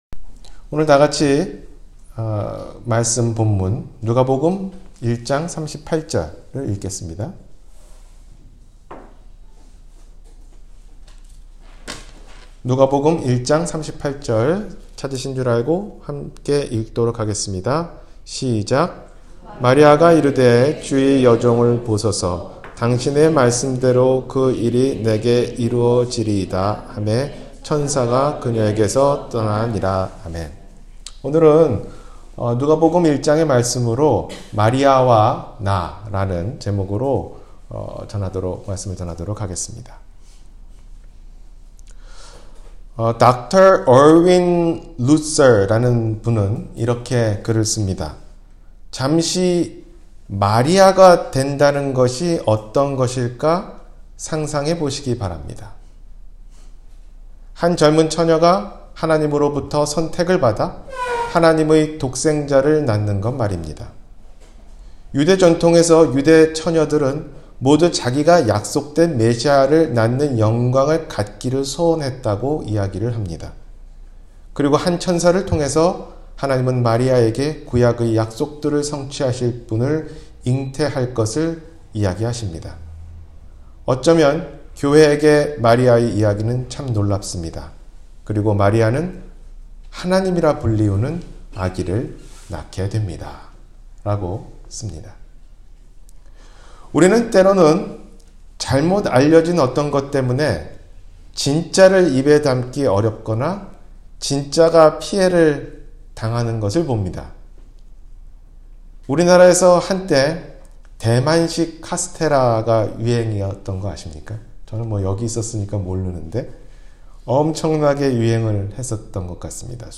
마리아와 나 – 주일설교